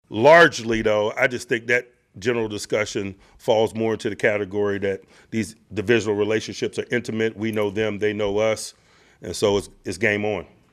Tomlin held his weekly media event yesterday, and said his sole focus is on the Browns, not on the fact that the Steelers will play two AFC North rivals in five days…Cleveland on Sunday and Cincinnati on Thursday, October 16th.